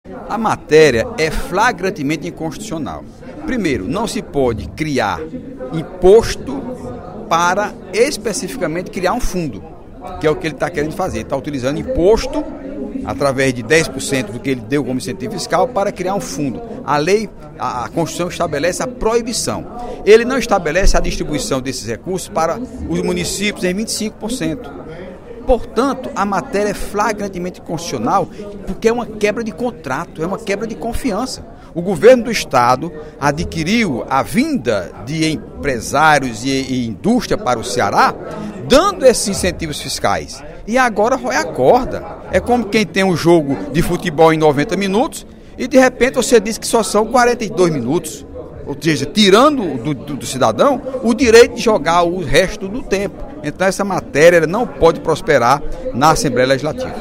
O deputado Heitor Férrer (PSB) anunciou, nesta terça-feira (12/07), durante o primeiro expediente da sessão plenária  da Assembleia Legislativa, que vai pedir audiência pública, com o objetivo de discutir o projeto de lei do Governo do Estado que prevê a criação do Fundo de Equilíbrio Fiscal.